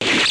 1 channel
Zap.mp3